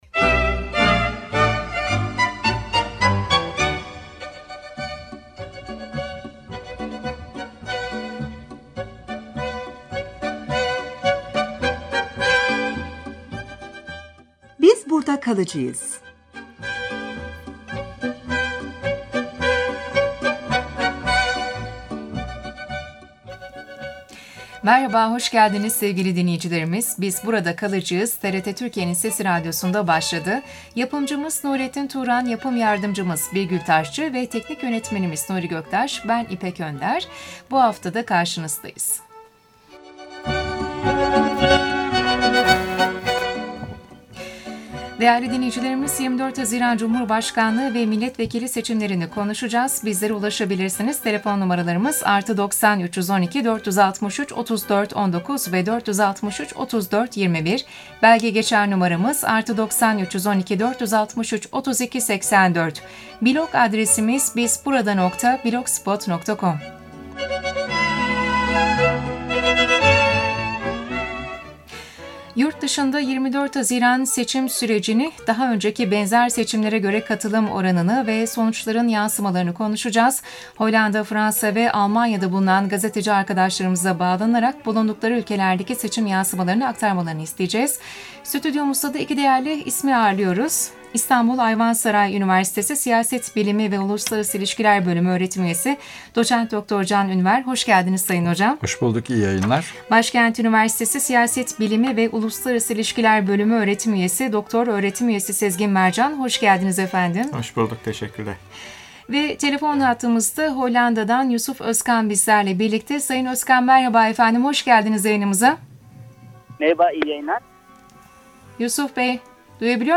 Radyo yayın kaydına bu sayfadan ulaşabilirsiniz.